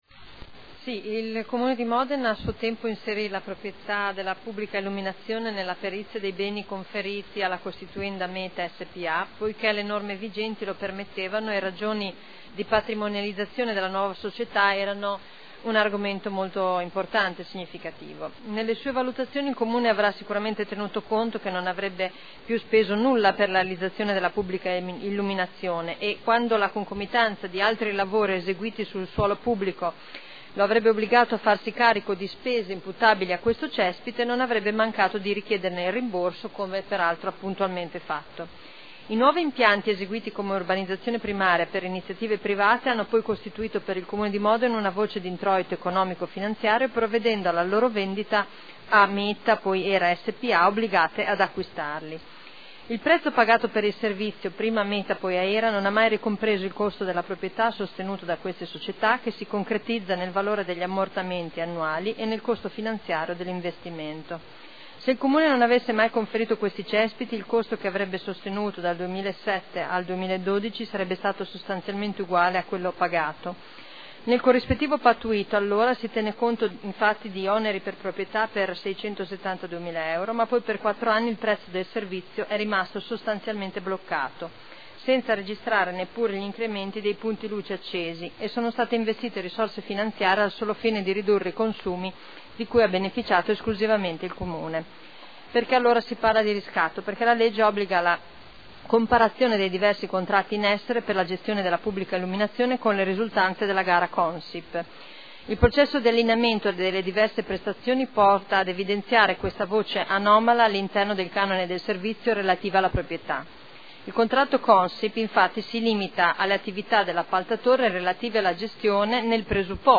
Seduta del 31/10/2013.